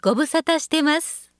ホームページ作成で利用できる、さまざまな文章や単語を、プロナレーターがナレーション録音しています。